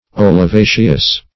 Search Result for " olivaceous" : The Collaborative International Dictionary of English v.0.48: Olivaceous \Ol`i*va"ceous\, a. [L. oliva olive.]